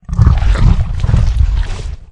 flesh_eat_2.ogg